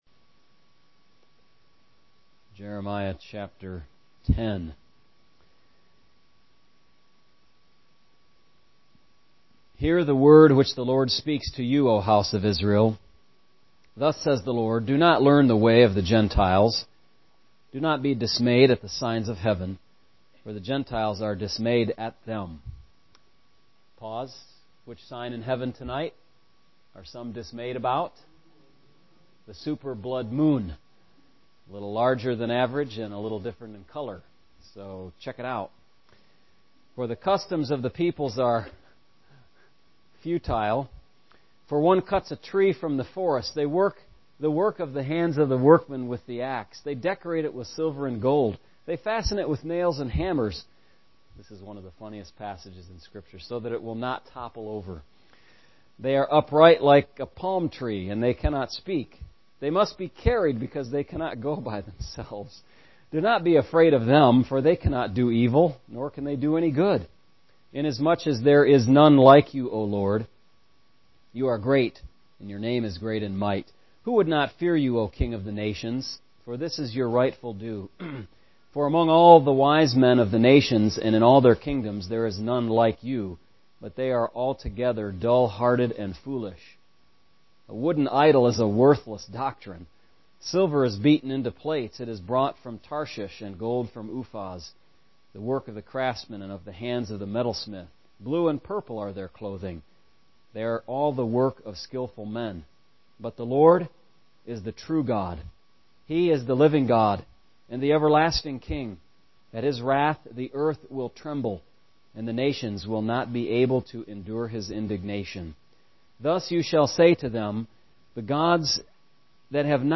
MP3 recordings of sermons and Bible studies for the Sunday ministries at the church.